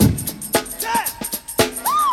76 Bpm Drum Groove C# Key.wav
Free breakbeat sample - kick tuned to the C# note. Loudest frequency: 3650Hz
76-bpm-drum-groove-c-sharp-key-v6A.ogg